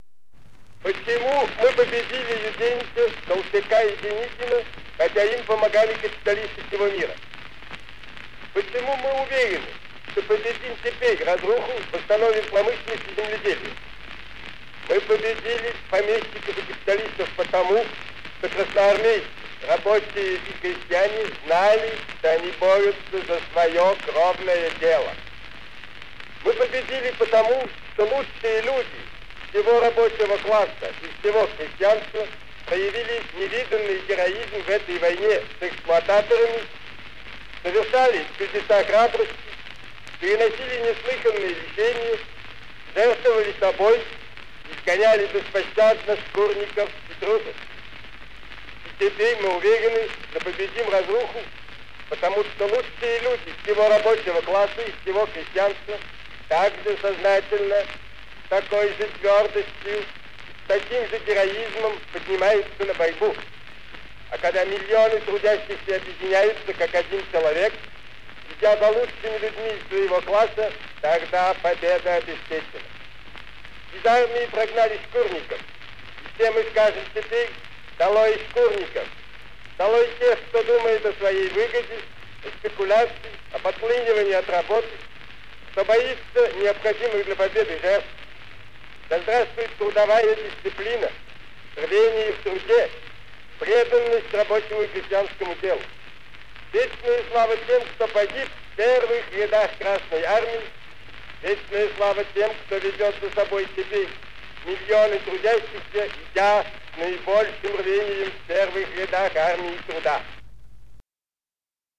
Lenin talks about goals of the working class
Recorded in Mar. 1919.